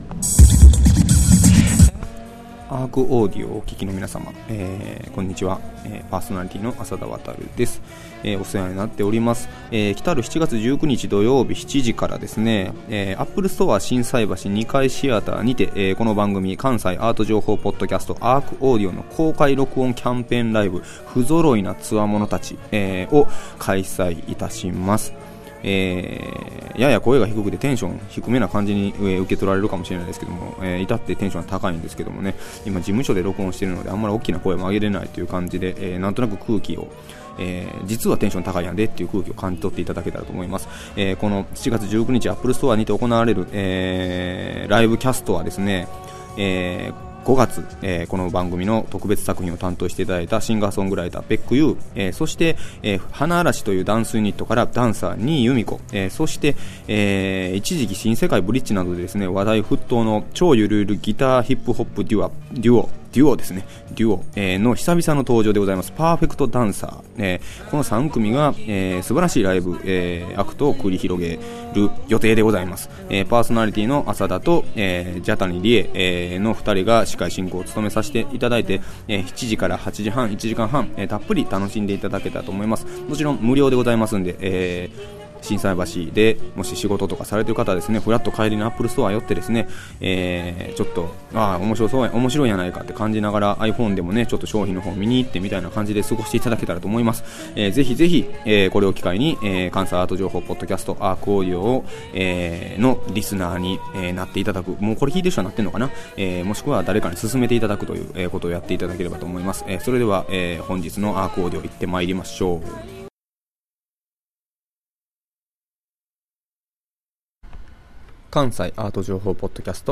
毎週火曜日は築港ARCにて毎月開催されるアートと社会を繋ぐトークイベント「ARCトークコンピレーション」の模様を全4回に分けてお届けします。今月のテーマはずばり！「中高生に伝えるアートフリーペーパーの可能性」。